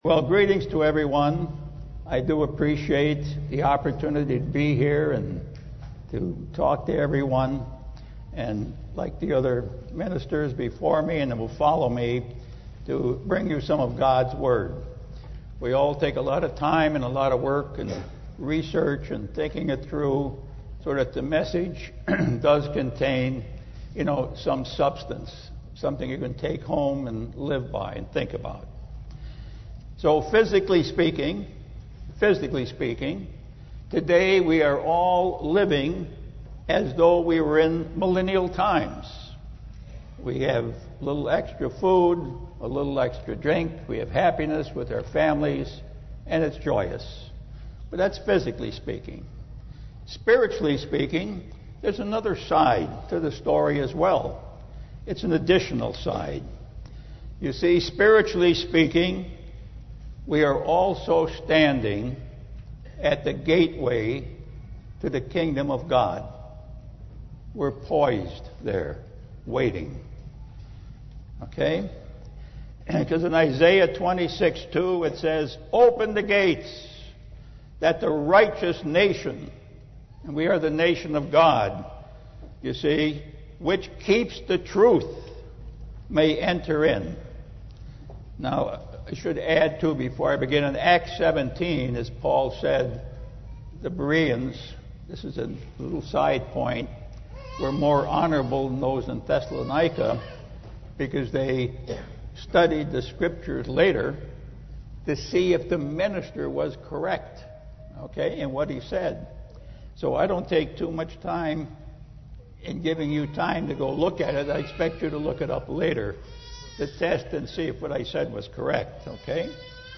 This message, given during the Feast of Tabernacles, looks forward to the coming Kingdom of God and explains why the need for this great rescue mission and how it was and will it be accomplished.
This sermon was given at the Oceanside, California 2017 Feast site.